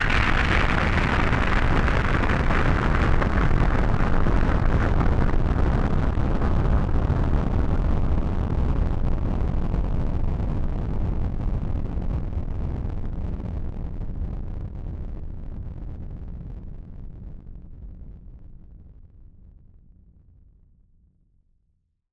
BF_DrumBombB-04.wav